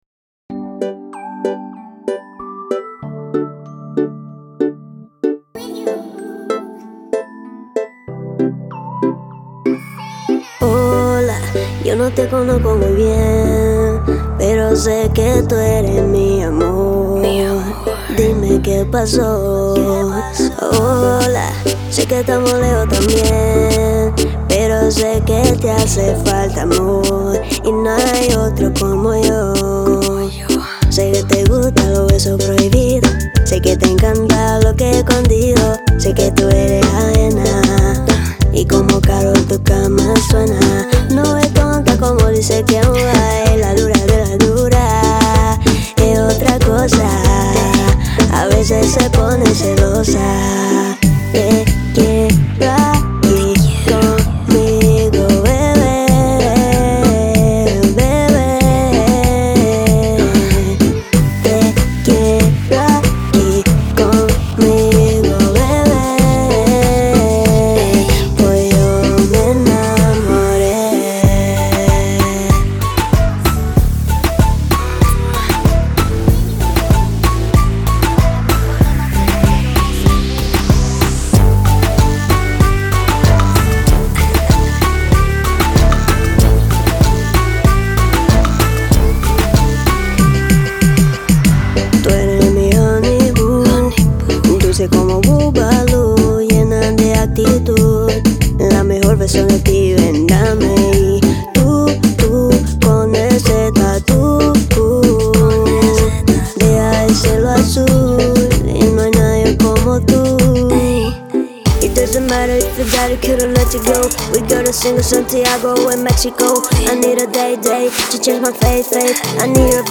EstiloReggaeton